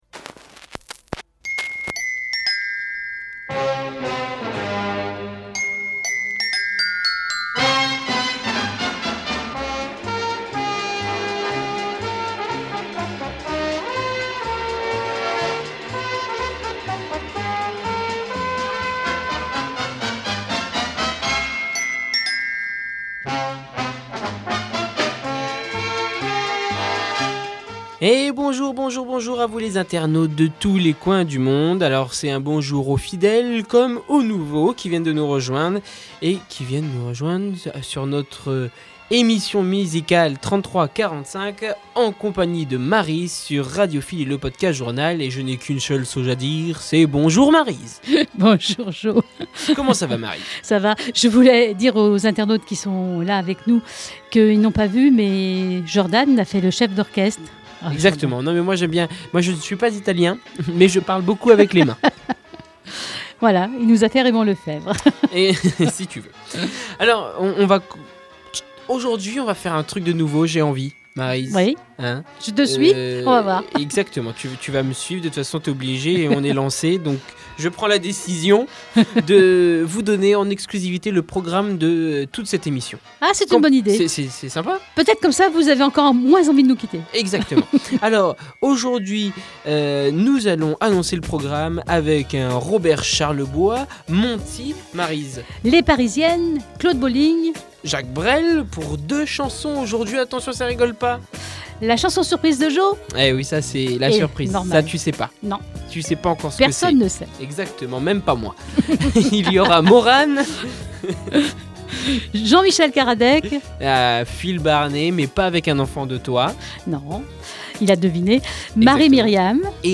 La différence, c'est de vous faire écouter les faces cachées passées aux oubliettes, des titres étouffés par les tubes de ces années vinyles. Revisiter cette fourmilière de chansons à textes sans autre prétention que l'envie de vous faire découvrir ou redécouvrir ces richesses, qui incroyablement, malgré le temps, restent intemporelles!